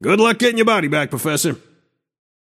Shopkeeper voice line - Good luck gettin‘ your body back, Professor.